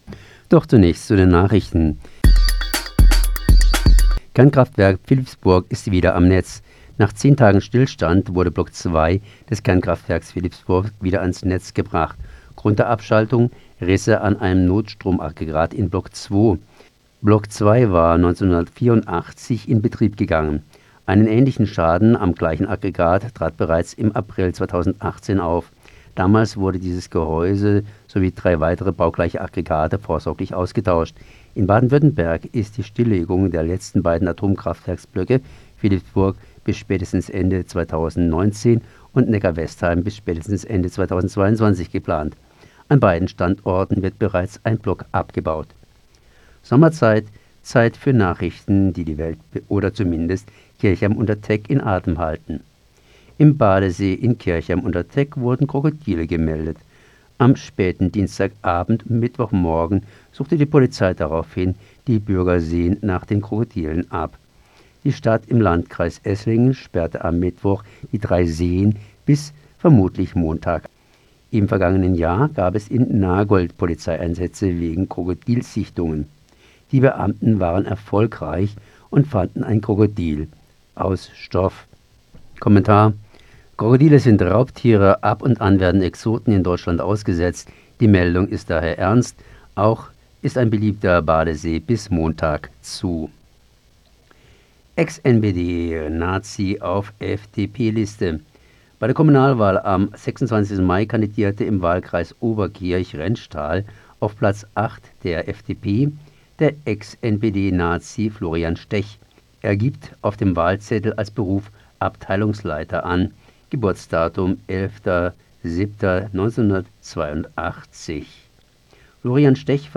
Fokus Südwest 20.06.2019 Nachrichten